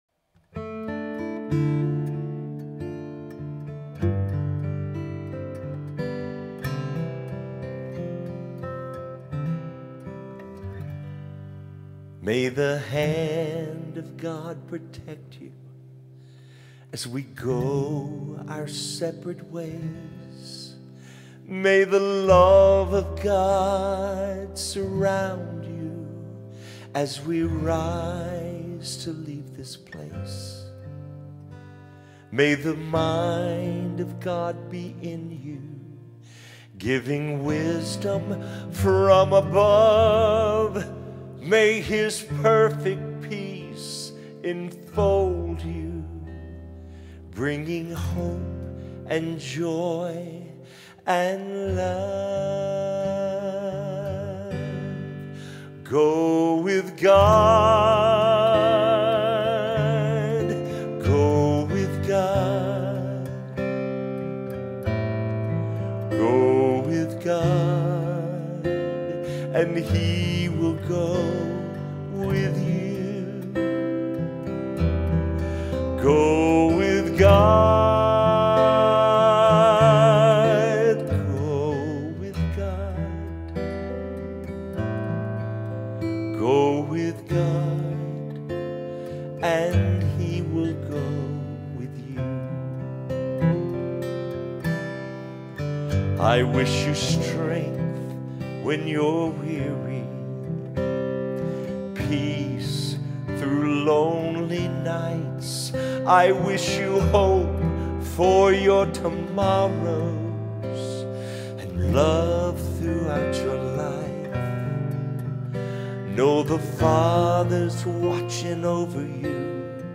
Universal Gospel